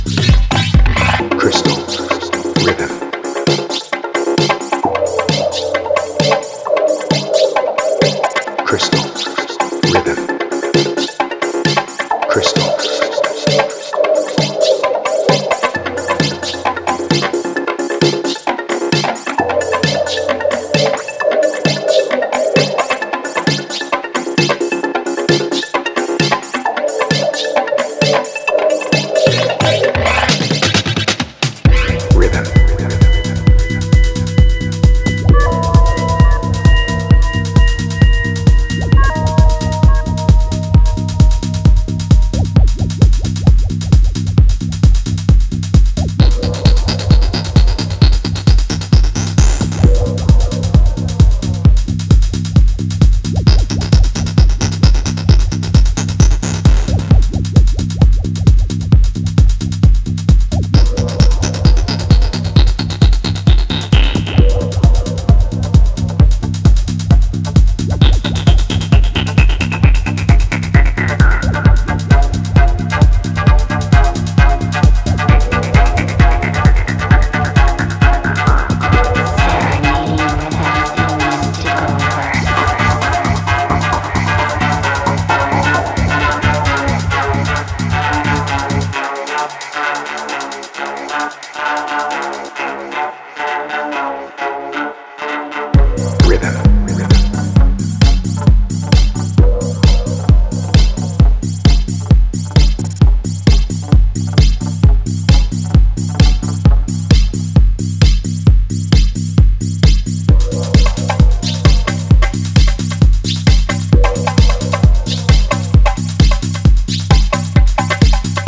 ドロドロのベースラインにそこはかとない狂気を滲ませたディープ・トランス・ハウス